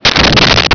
Sfx Whoosh 4902
sfx_whoosh_4902.wav